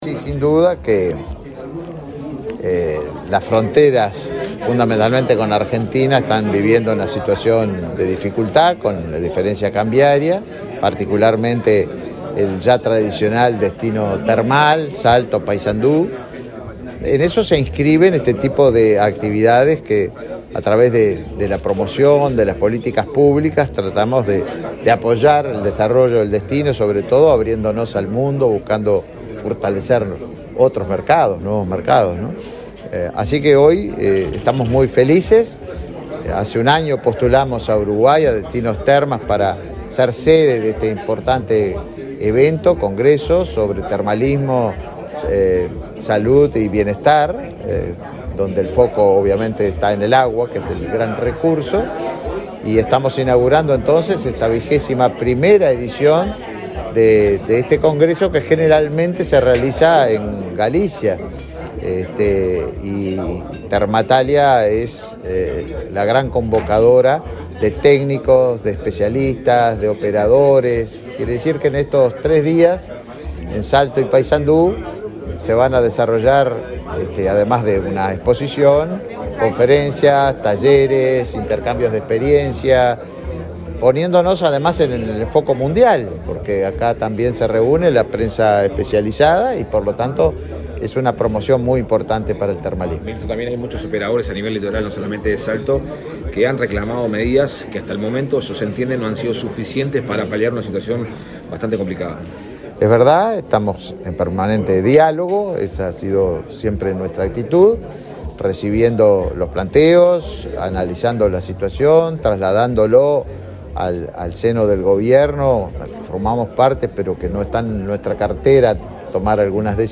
Declaraciones del ministro de Turismo, Tabaré Viera
El ministro de Turismo, Tabaré Viera, dialogó con la prensa en Salto, antes de participar en la apertura del 21.° Encuentro Internacional del Turismo